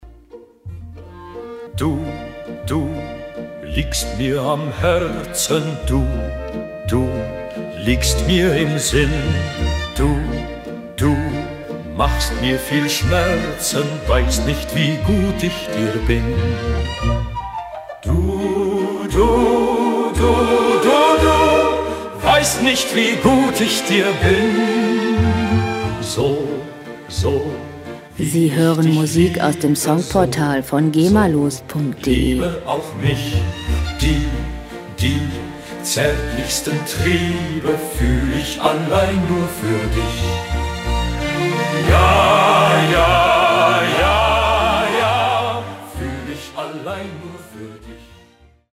gema-freie Songs aus der Rubrik "Volkslieder"
Musikstil: Heimat-Schlager
Tempo: 172 bpm
Tonart: B-Dur
Charakter: romantisch, gefühlvoll
Instrumentierung: Orchester, Gesang